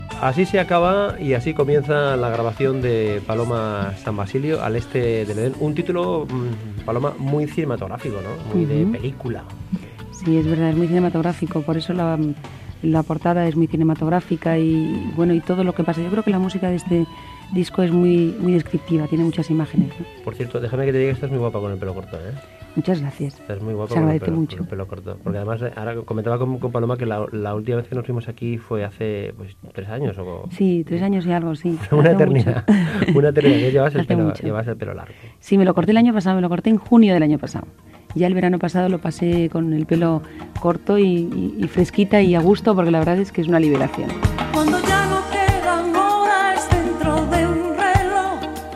Inici de l'entrevista a la cantant Paloma San Basilio, tot parlant del tall de cabell que s'ha fet
Entreteniment